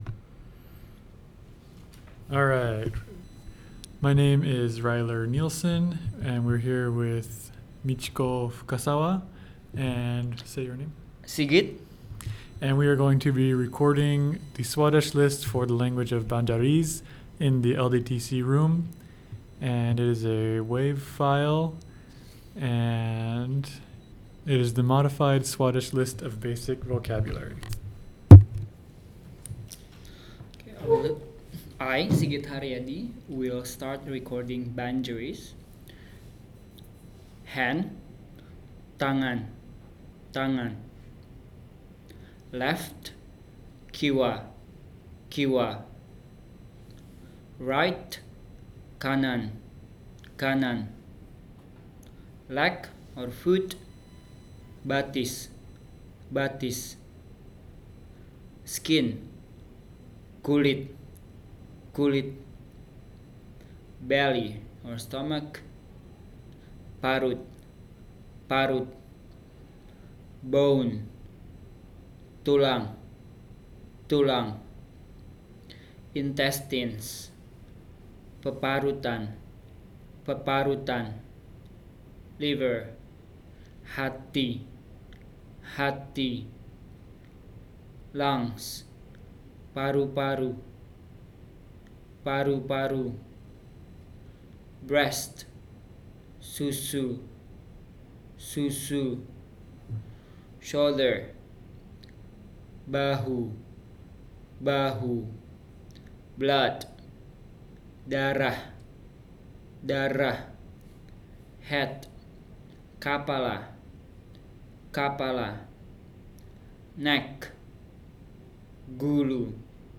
digital wav file recorded at 44.1 kHz/24-bit with TASCAM DR-05
Indonesia; recording made in Honolulu, Hawaii